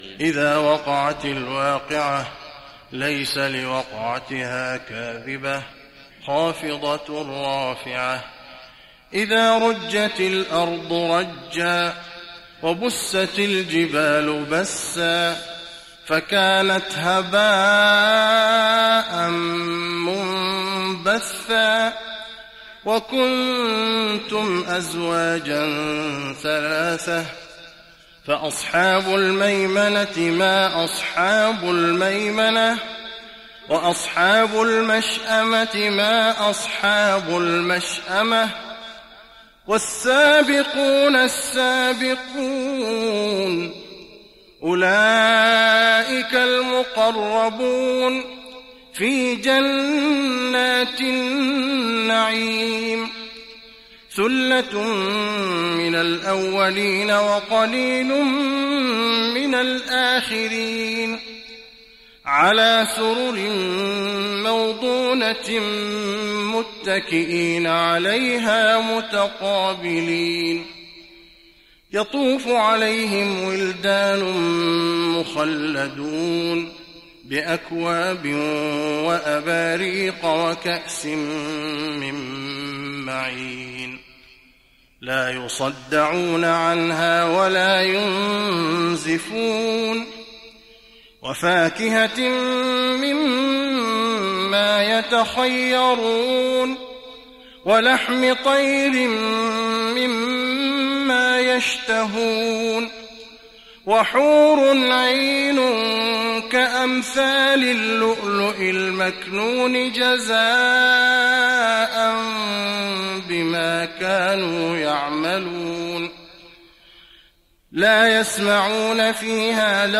تراويح رمضان 1415هـ سورتي الواقعة و الحديد Taraweeh Ramadan 1415H from Surah Al-Waaqia to Surah Al-Hadid > تراويح الحرم النبوي عام 1415 🕌 > التراويح - تلاوات الحرمين